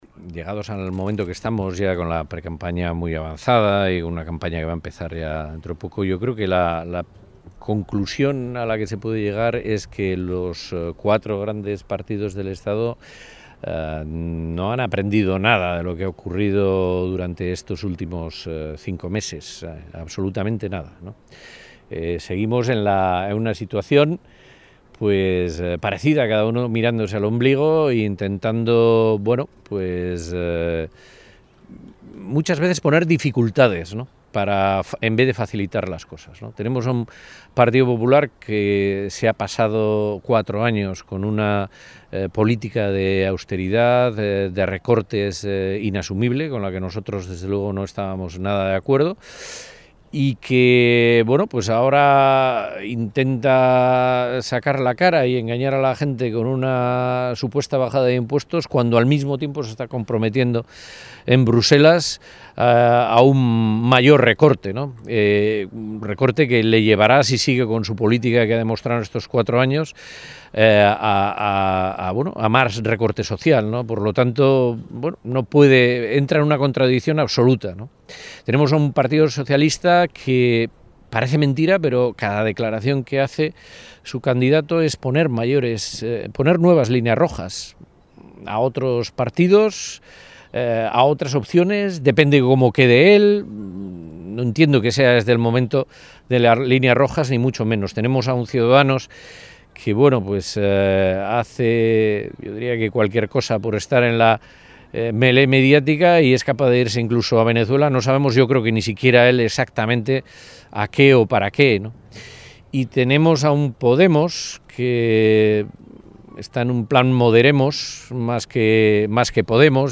En declaraciones a los medios de comunicación en Algorta, el candidato jeltzale ha señalado que el Partido Nacionalista Vasco “garantiza una acción coordinada para defender y luchar por los intereses de Euskadi en Madrid” y ha añadido que “esta Legislatura fallida ha servido para demostrar en qué está cada uno; y lo que es evidente es que solo el PNV pone encima de la mesa los problemas de los ciudadanos vascos, la agenda vasca”.